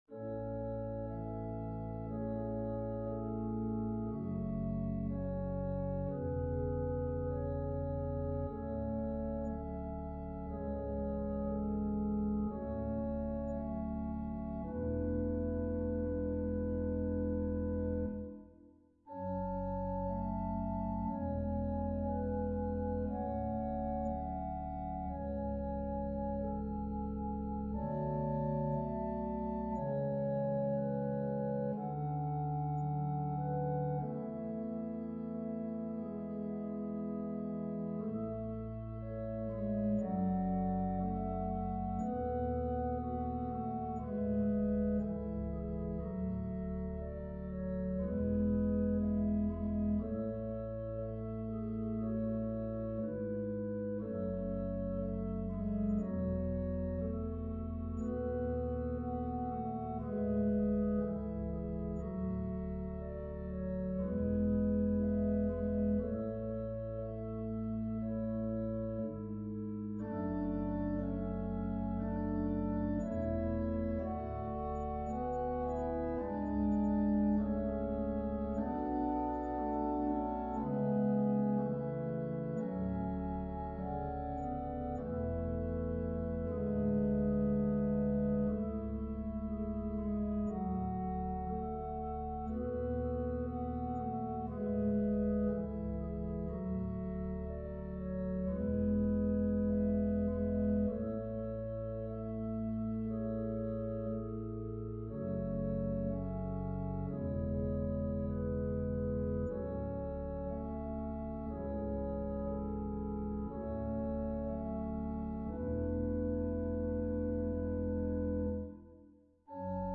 Voicing/Instrumentation: Organ/Organ Accompaniment
Lullabies